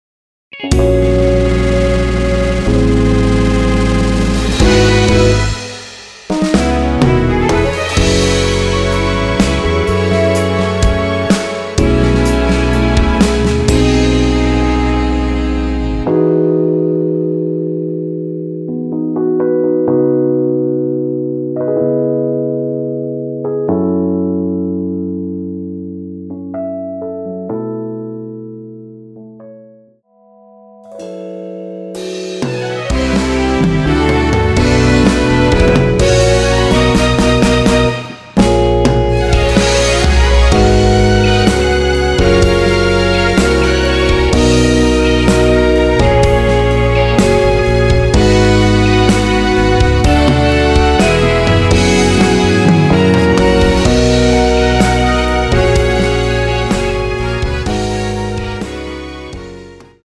남성분이 부르실수 있는 키로 제작 하였습니다.(미리듣기 참조)
앞부분30초, 뒷부분30초씩 편집해서 올려 드리고 있습니다.
중간에 음이 끈어지고 다시 나오는 이유는